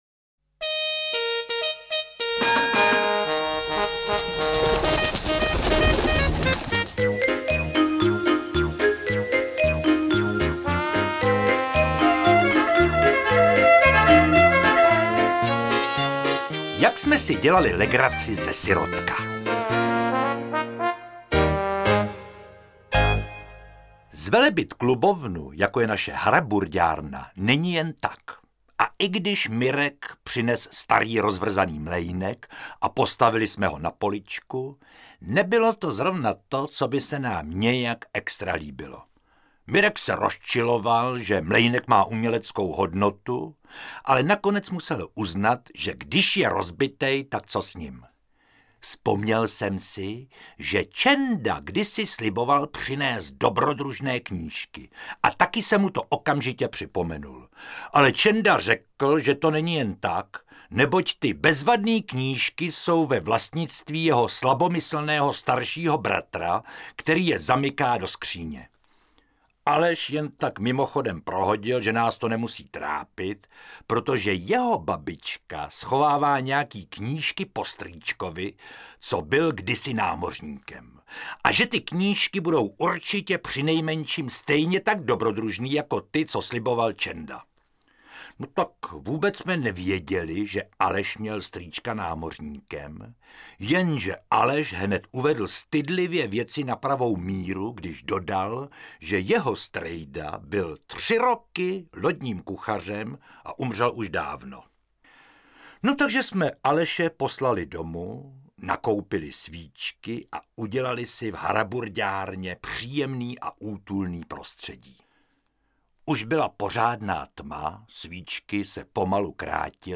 Interpret:  Josef Dvořák
Četby vybraných příhod z knihy Boříkovy lapálie se ujal herec a komik Josef Dvořák, který na zvukové nosiče namluvil již takové "hity" jako Maxipes Fík nebo Bob a Bobek.